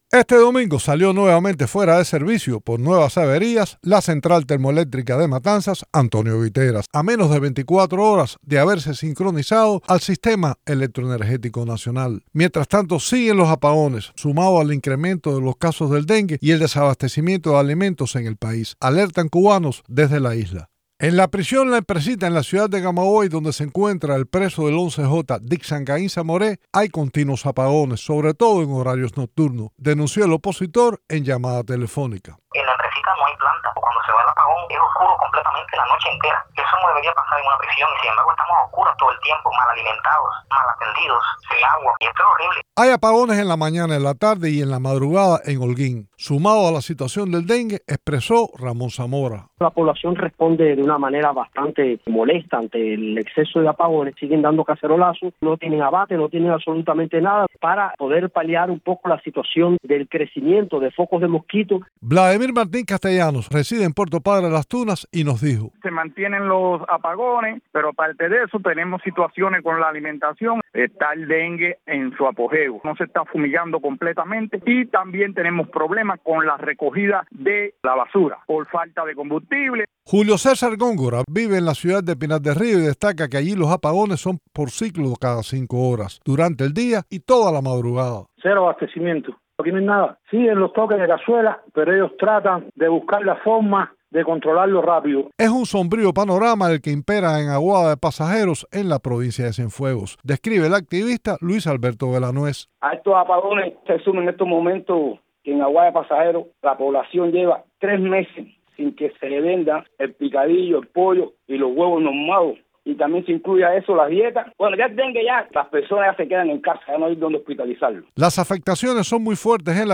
En Cuba siguen los apagones, en medio del incremento de los casos de dengue y el desabastecimiento de alimentos, una crisis ya prolongada que desgasta a las familias, alertaron a Radio Televisión Martí residentes en la isla.